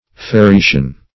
Farreation \Far`re*a"tion\, n.